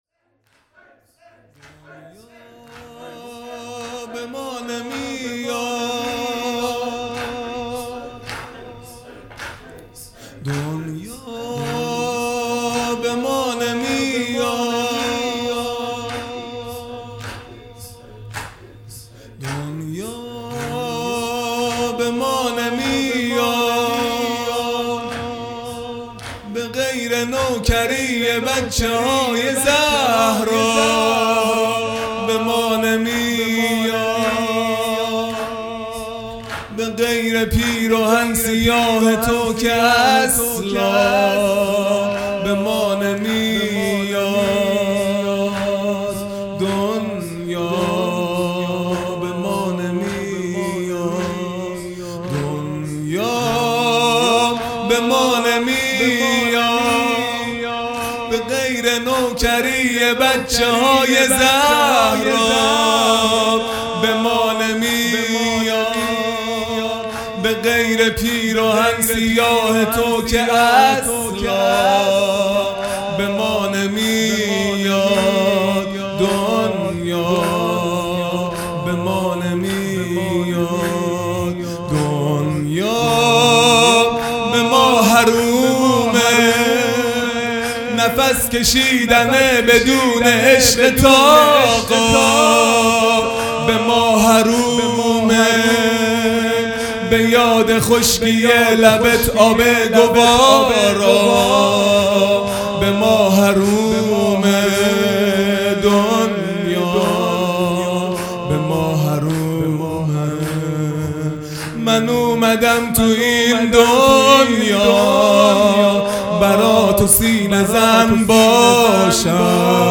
خیمه گاه - هیئت بچه های فاطمه (س) - زمینه اول | دنیا به ما نمیاد
دهه اول محرم الحرام ۱۴۴٢ | شب ششم